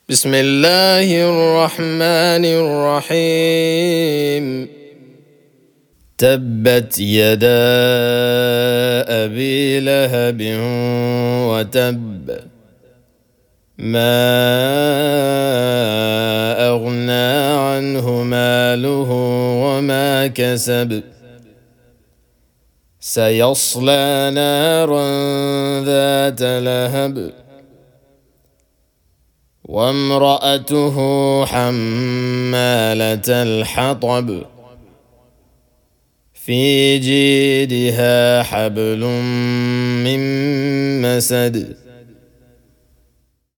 Captions English Recitation of Al-Masad, Hafs an Asim, murattal.
Chapter_111,_Al-Masad_(Murattal)_-_Recitation_of_the_Holy_Qur'an.mp3